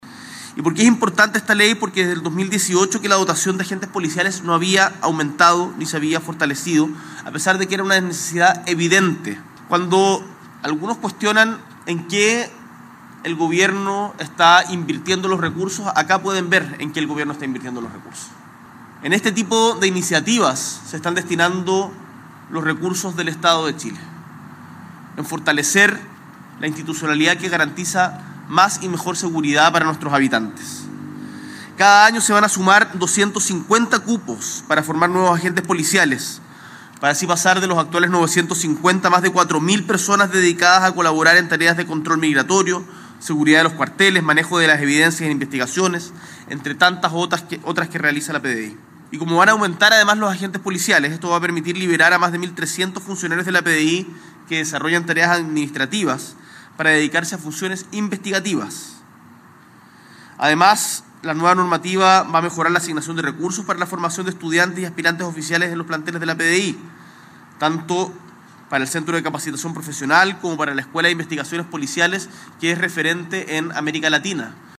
Este martes 25 de febrero, en la Escuela de Investigaciones Policiales, el Presidente Gabriel Boric Font, encabezó la promulgación de la Ley que moderniza el escalafón de Agentes Policiales.
El Presidente argumentó la importancia de esta iniciativa.